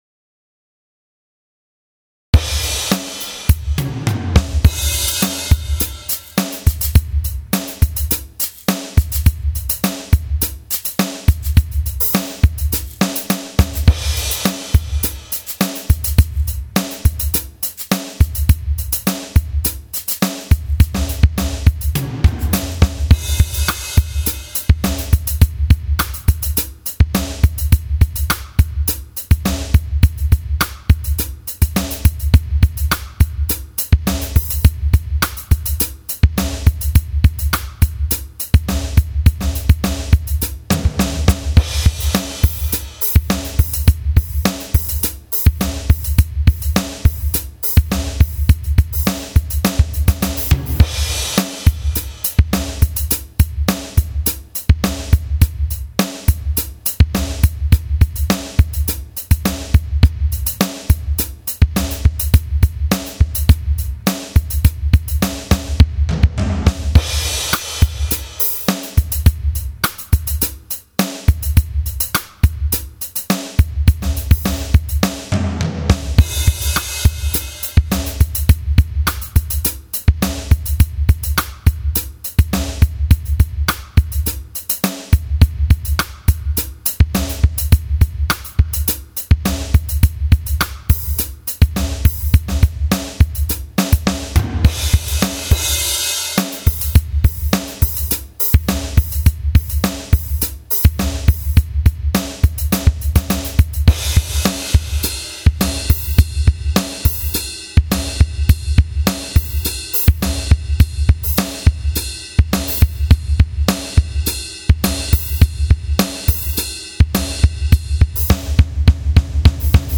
Drum Grooves
23 Synco Rock 104.mp3